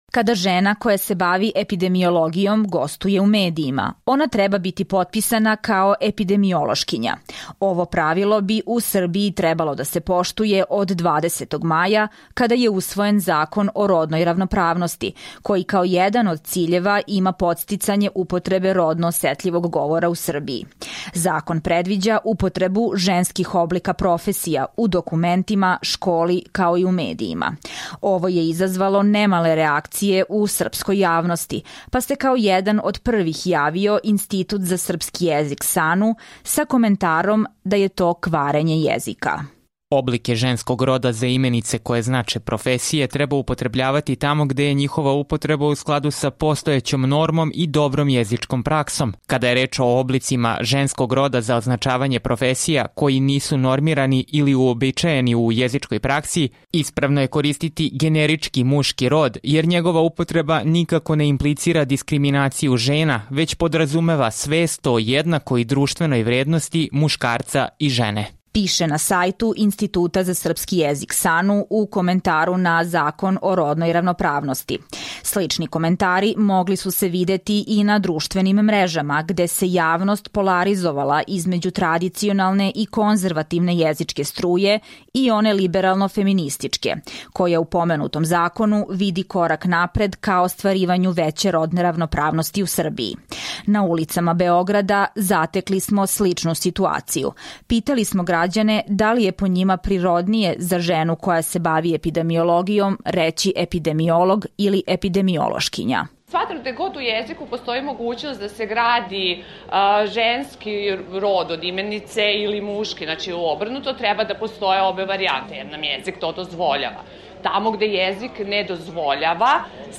Pitali smo građane i građanke šta o tome misle.